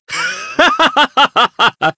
Kain-Vox_Happy3_jp.wav